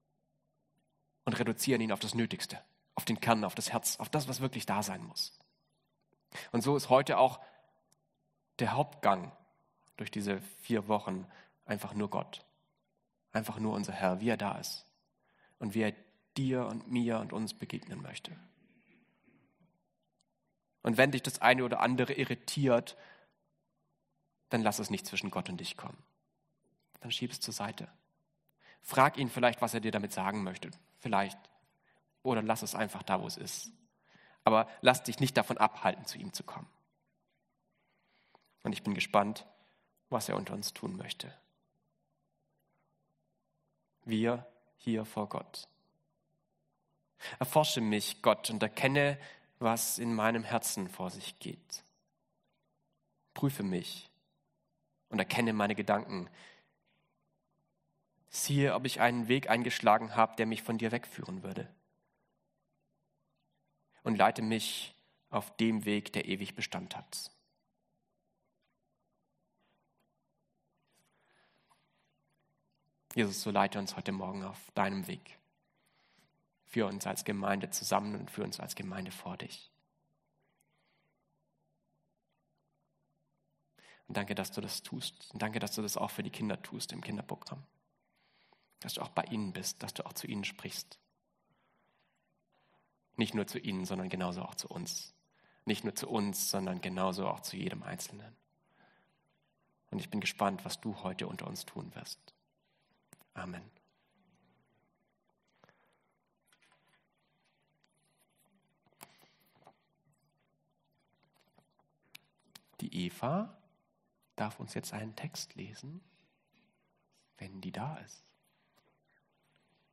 Passage: Joh 11, 17-37 Dienstart: Gottesdienst